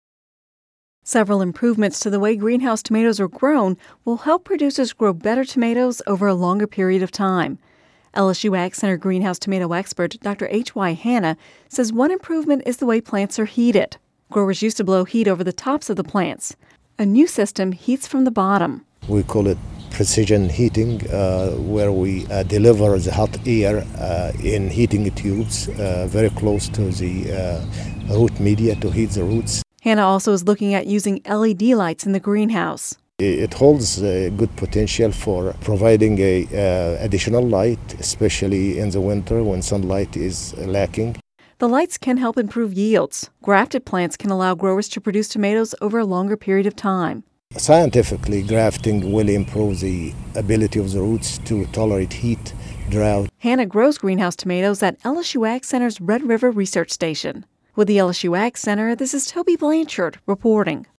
(Radio News 07/12/10) Several improvements to the way greenhouse tomatoes are grown will help producers grow better tomatoes over a longer period of time.